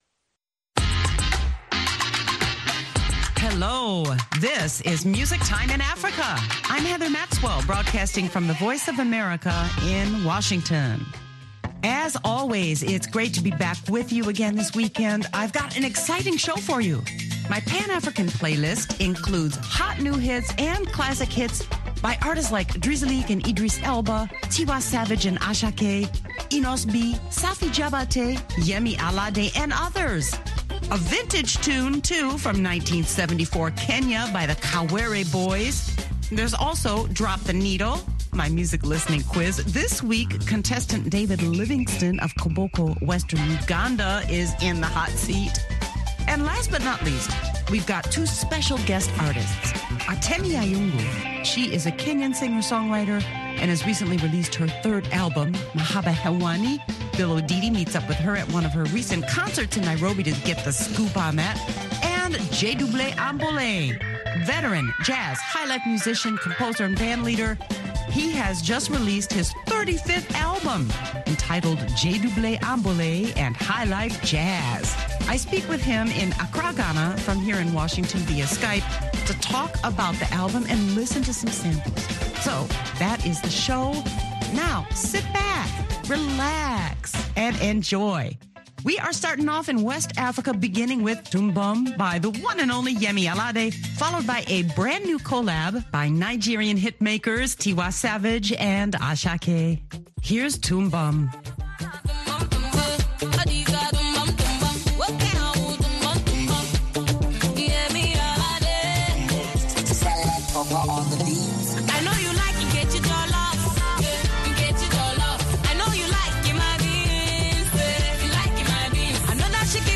Feature interviews